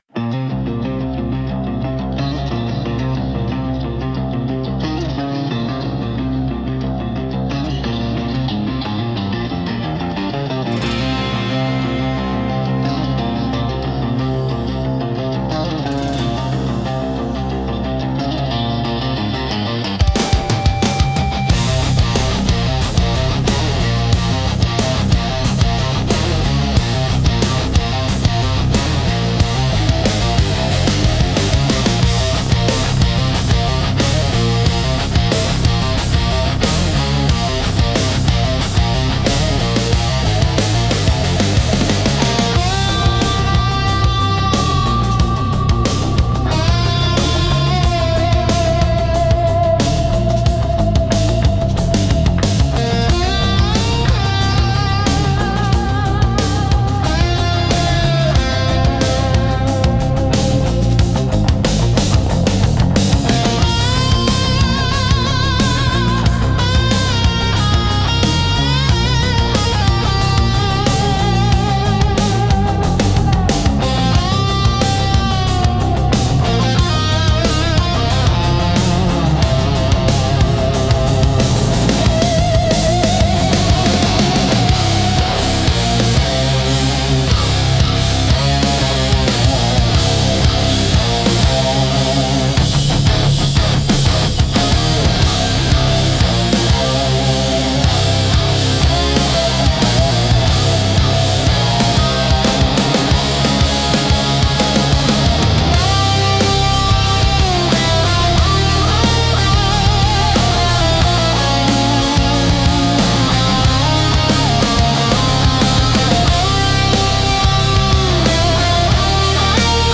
Instrumental - Signal Lost, Tone Found